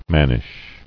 [man·nish]